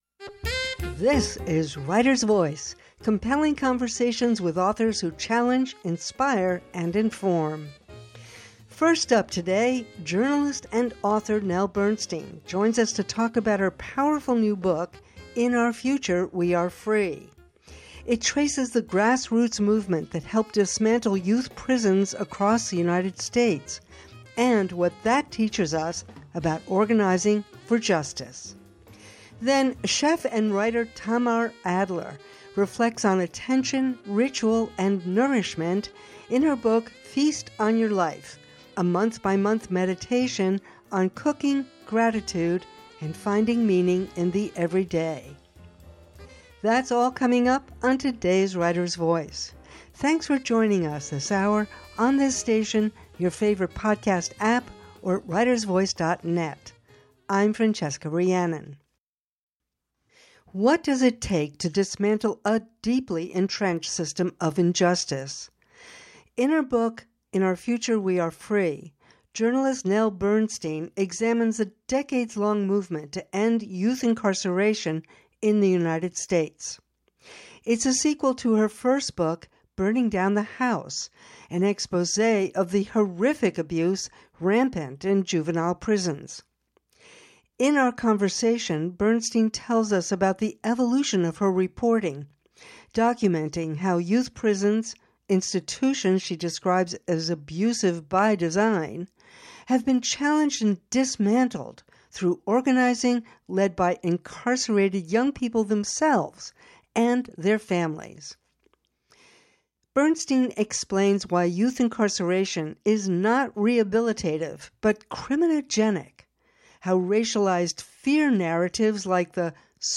Writer’s Voice: compelling conversations with authors who challenge, inspire, and inform.